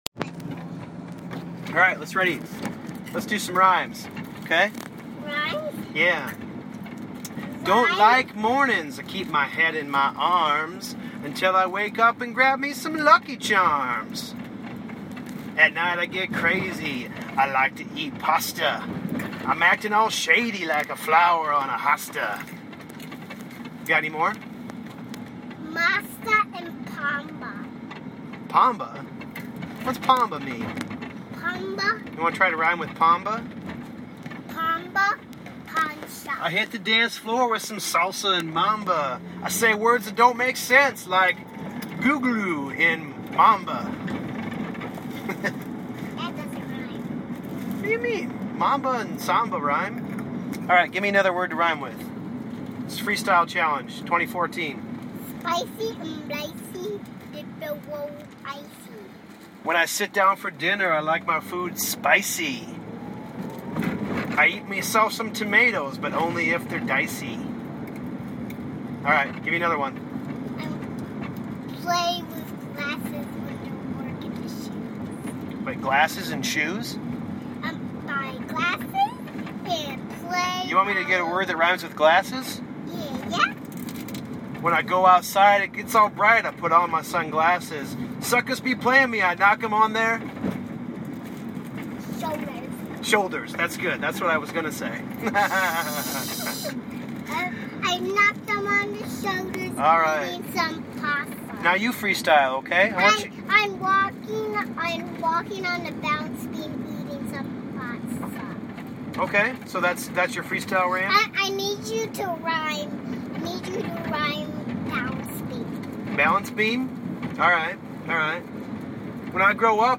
Impromptu car freestylin ....